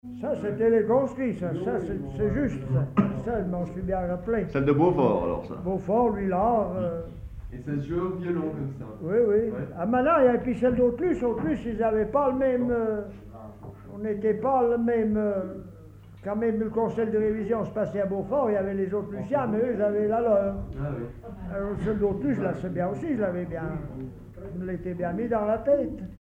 Commentaire sur les marches de conscrits
Catégorie Témoignage